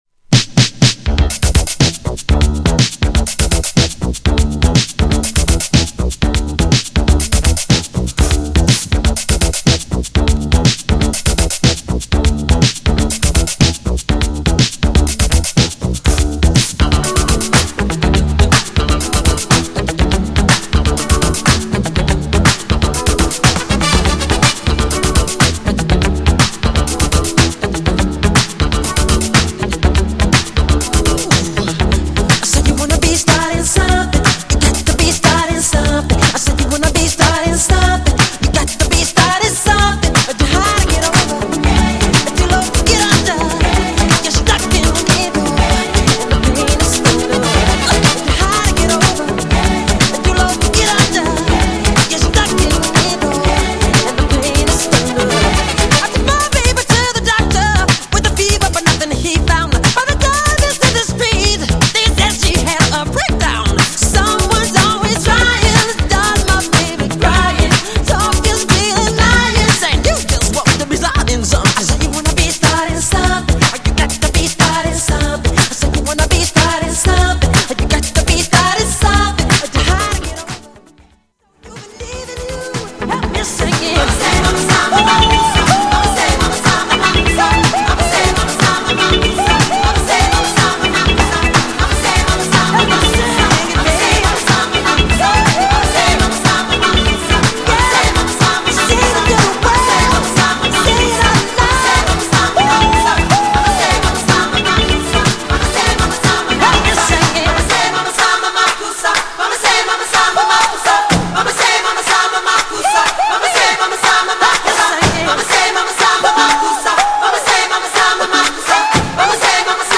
音質も最高!!
GENRE Dance Classic
BPM 121〜125BPM